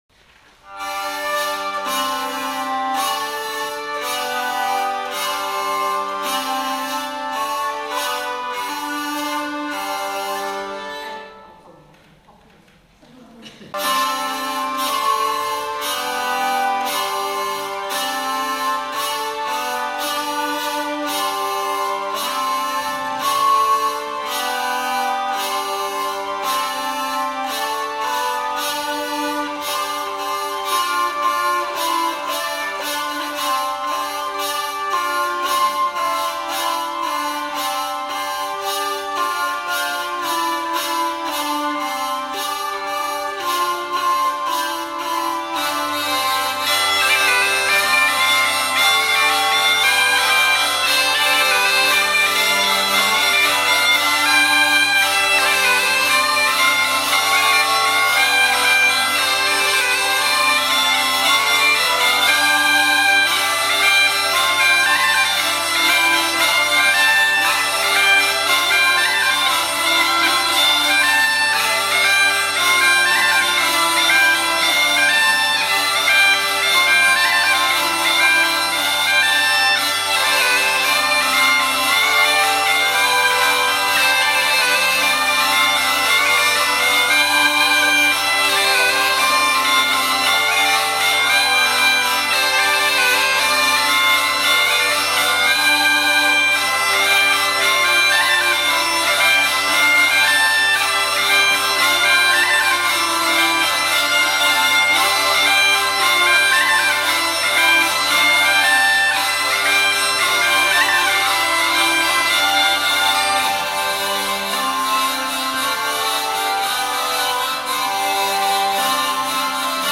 Fichiers "audio" du concert du 26 juillet 2006
Musique traditionnelle